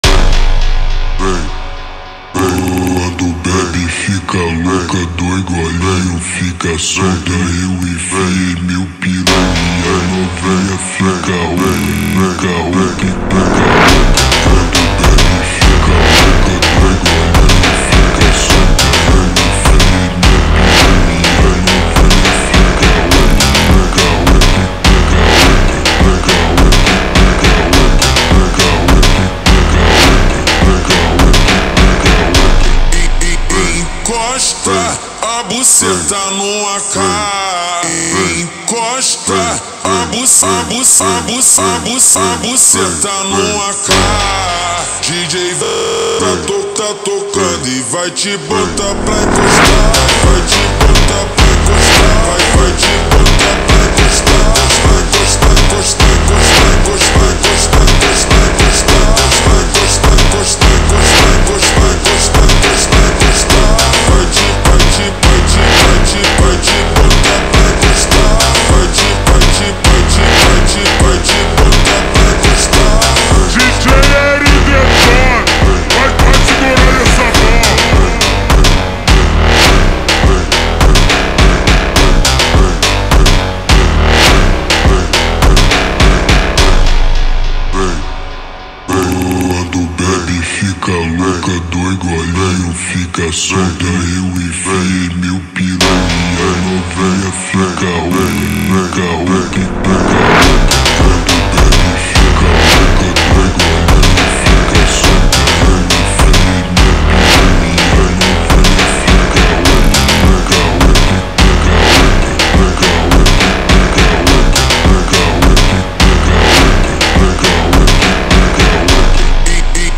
в замедленном варианте передает мощный эмоциональный заряд